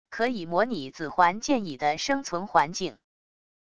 可以模拟紫环剑蚁的生存环境wav音频